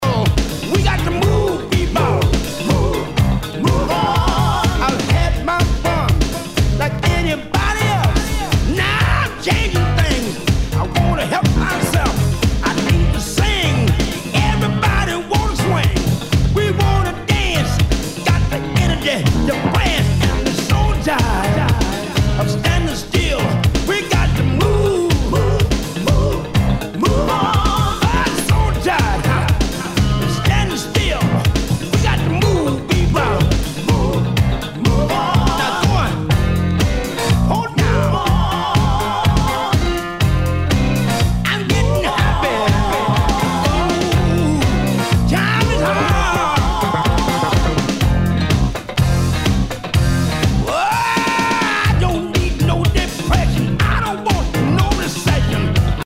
SOUL/FUNK/DISCO
ナイス！ファンク！